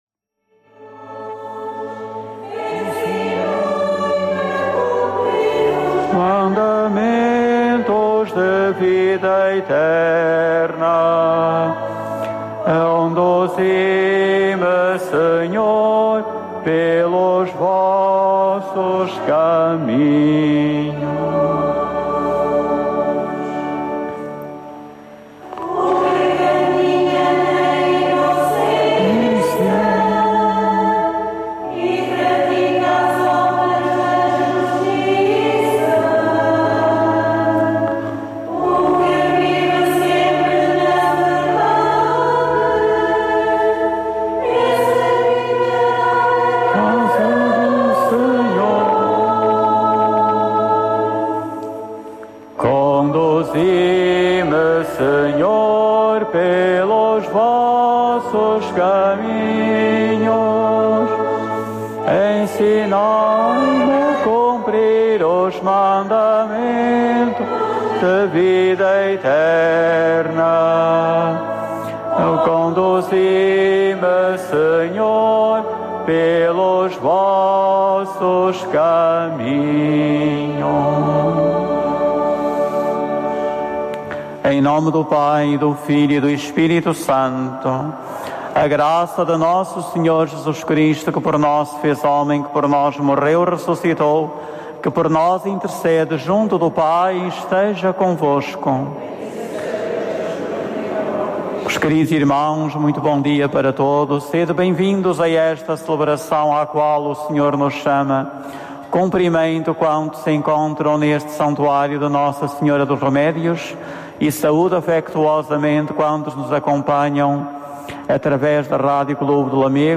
Eucaristia Dominical que foi transmitida diretamente do Santuário de Nossa Senhora dos Remédios, em Lamego.